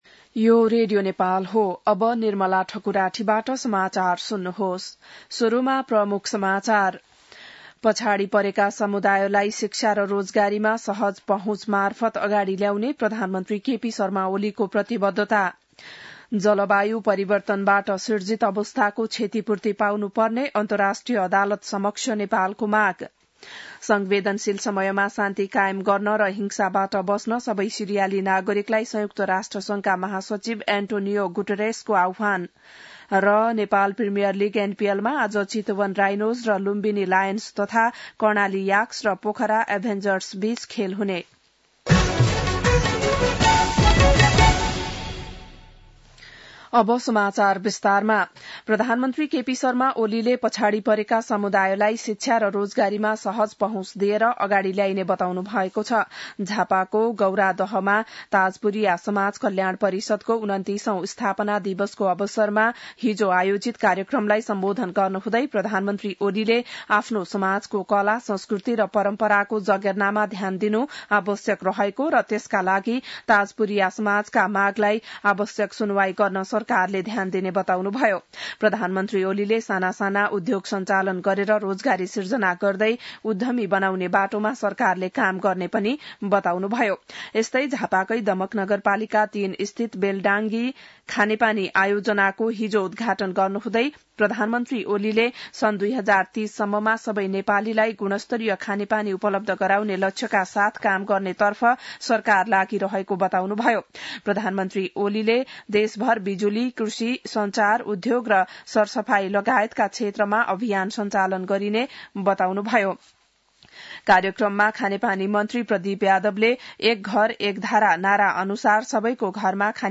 बिहान ९ बजेको नेपाली समाचार : २६ मंसिर , २०८१